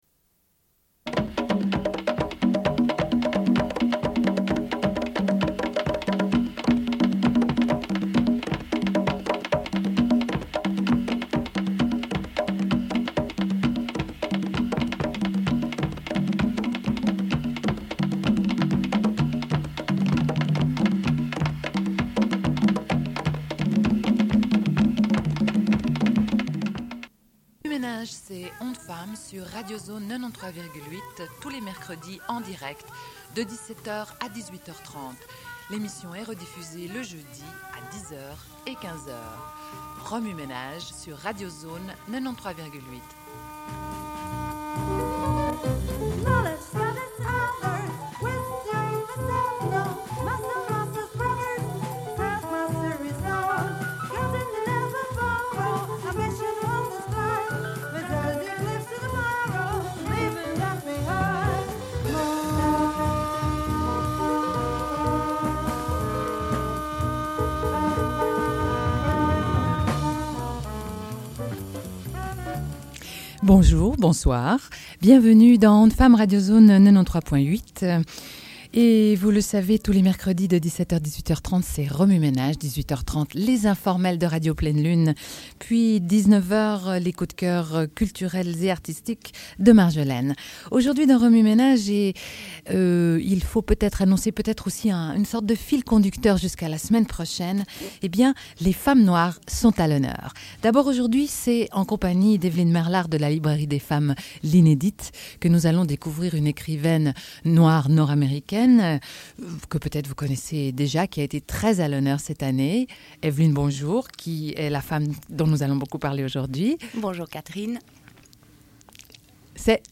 Une cassette audio, face A31:05
Au sujet de Beloved, Playing in the dark, et Jazz de Toni Morrison. Interview de Germaine Acogny, danseuse sénégalaise.